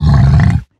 Minecraft Version Minecraft Version snapshot Latest Release | Latest Snapshot snapshot / assets / minecraft / sounds / mob / hoglin / angry3.ogg Compare With Compare With Latest Release | Latest Snapshot
angry3.ogg